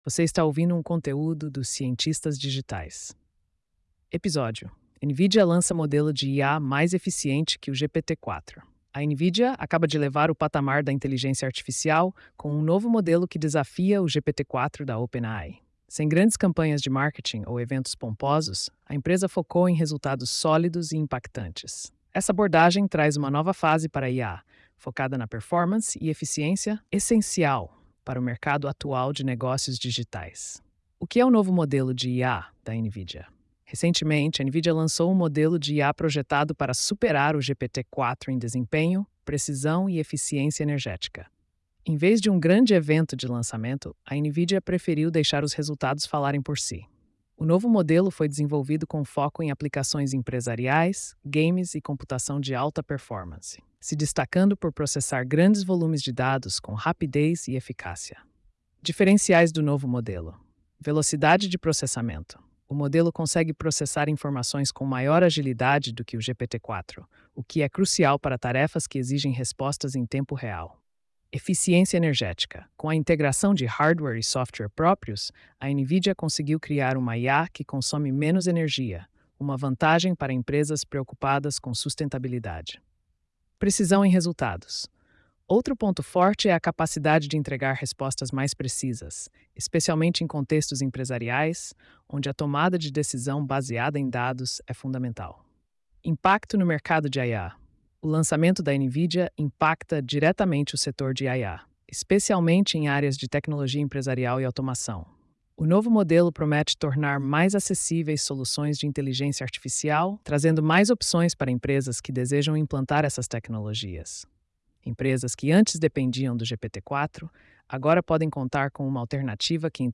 post-2580-tts.mp3